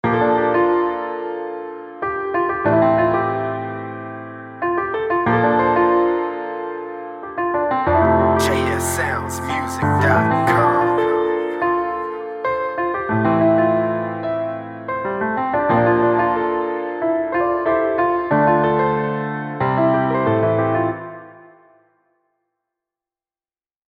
Soulful Piano Loop
piano